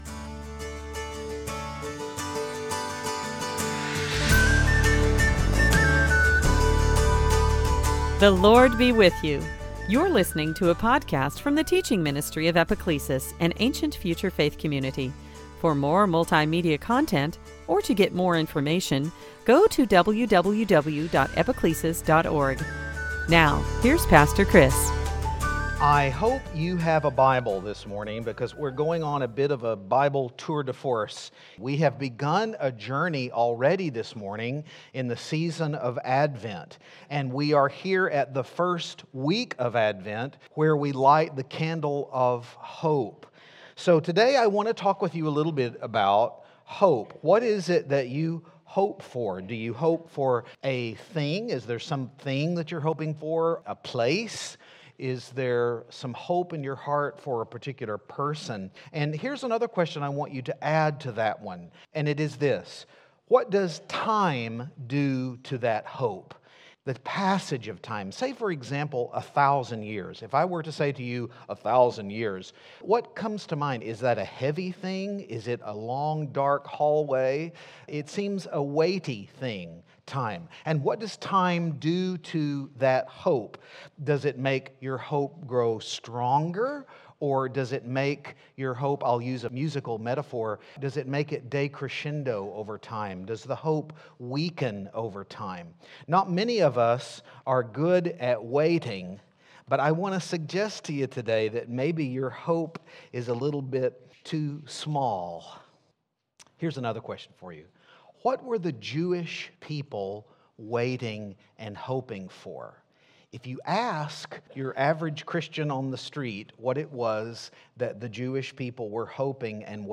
Series: Sunday Teaching
Service Type: Advent